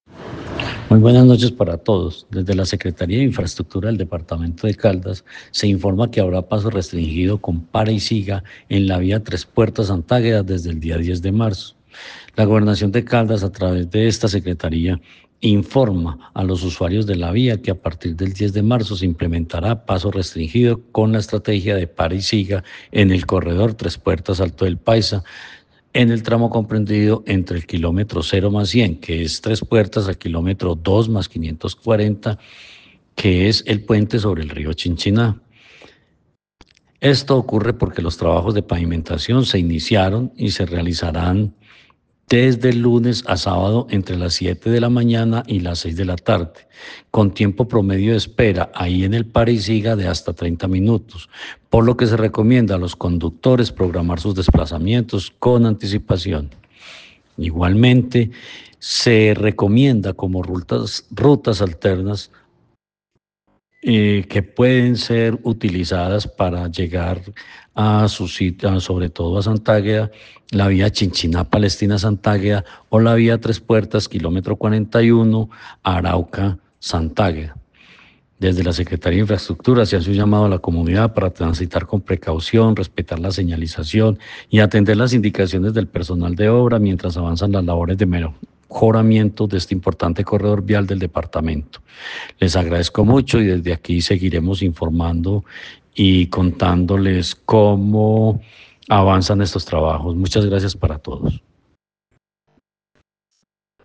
Jorge Ricardo Gutiérrez Cardona, secretario de Infraestructura de Caldas.